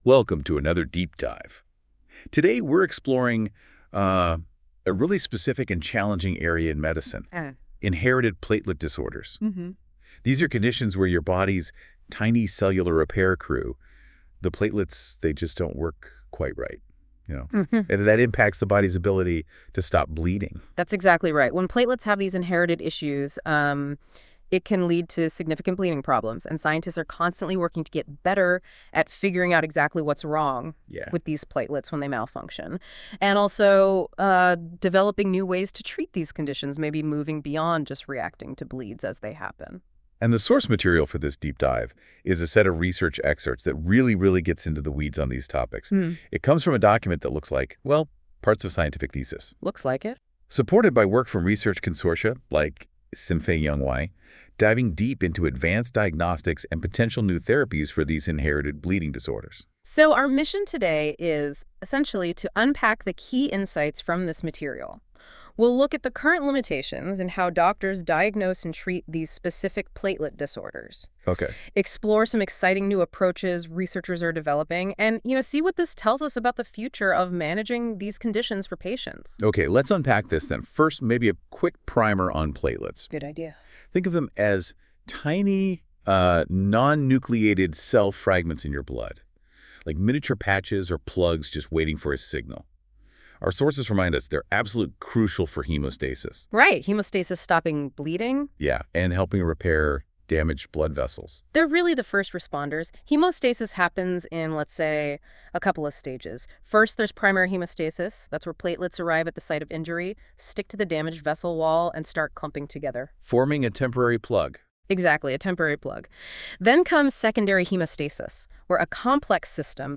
With AI a podcast is generated automatically from the thesis.